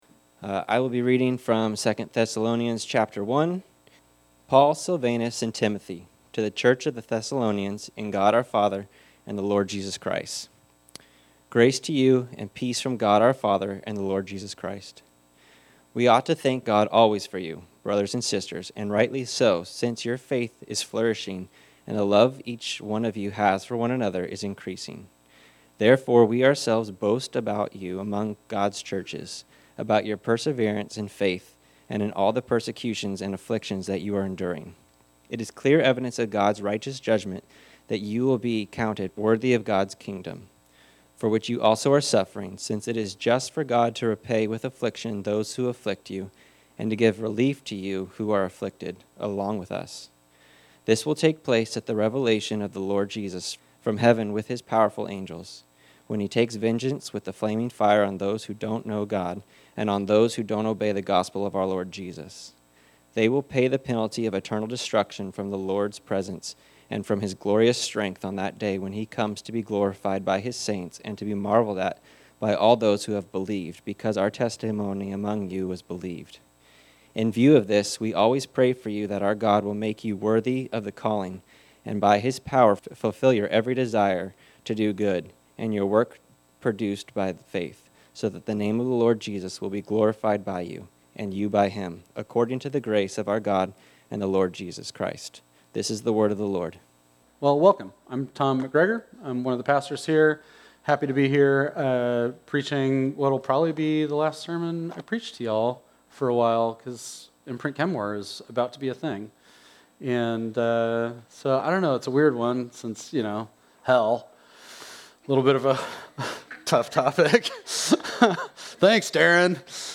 This sermon was originally preached on Sunday, August 31, 2025.